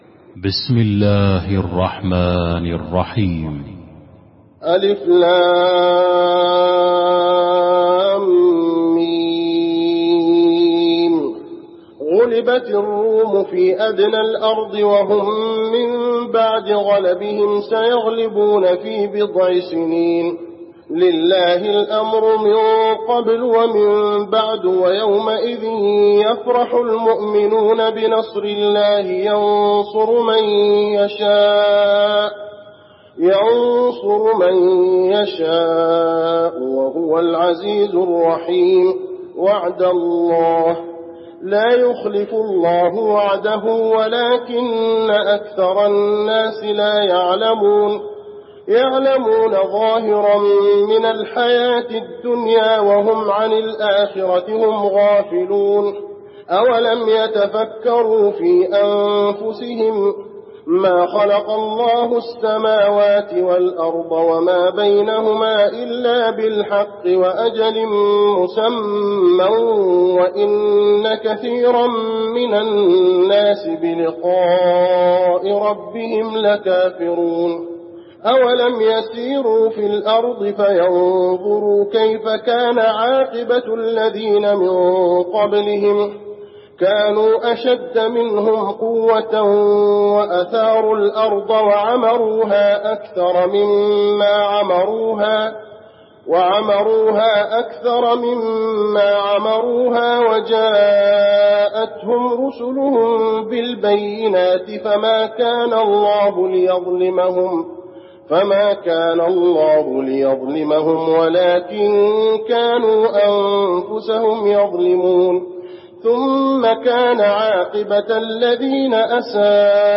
المكان: المسجد النبوي الروم